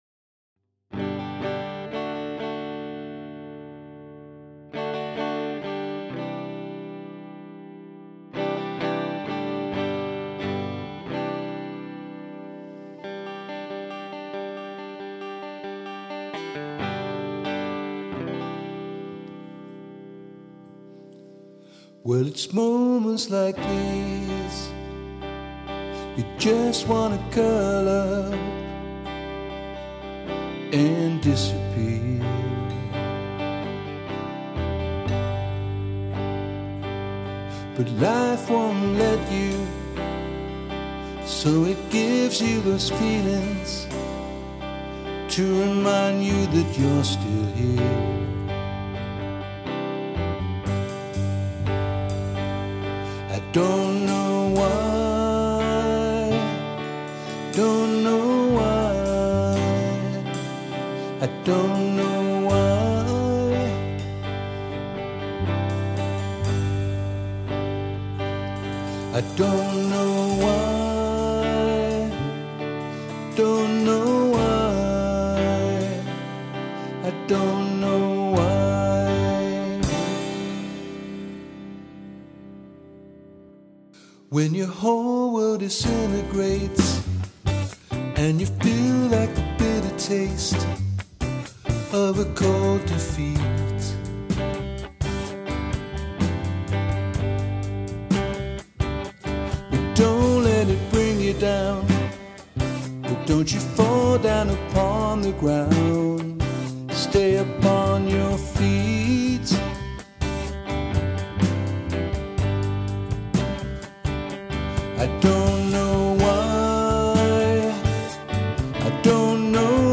Nous jouons essentiellement du rock, funk et reggae.